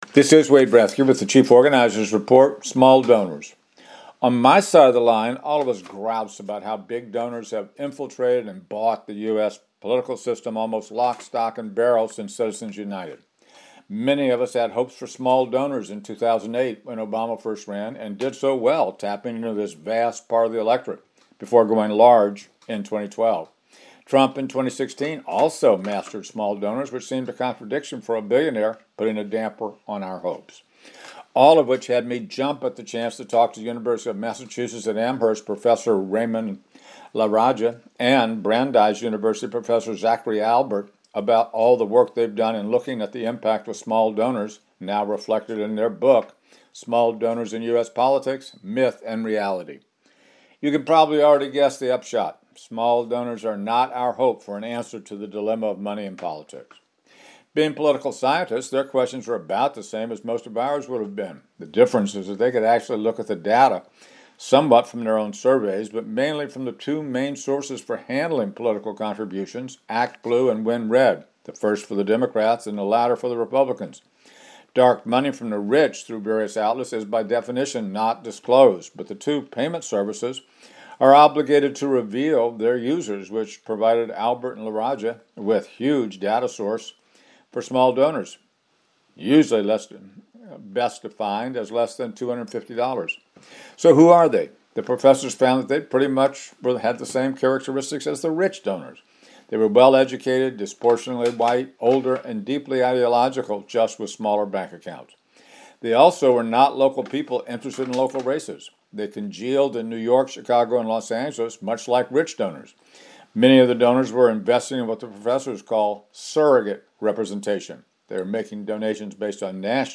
I realize that you might think that I’m putting words in their mouths, but I swear this is not only what they wrote in their book, but also the drum they beat on the radio.